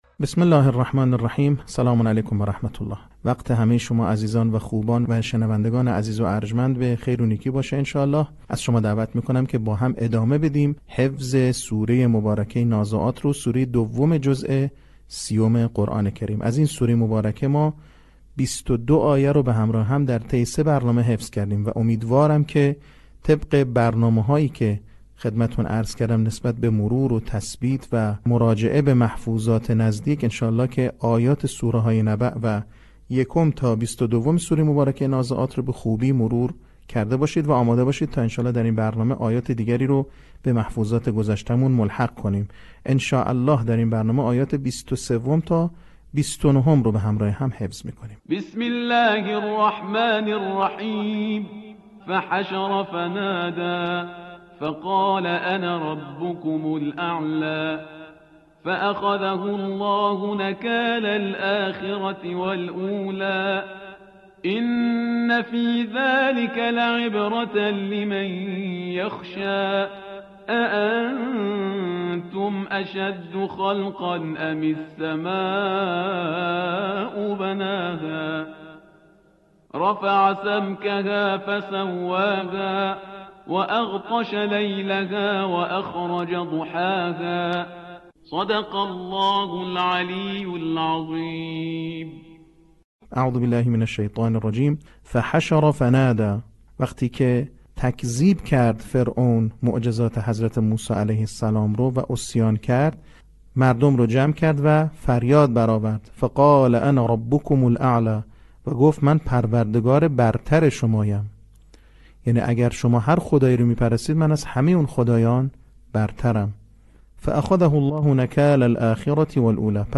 صوت | بخش چهارم آموزش حفظ سوره نازعات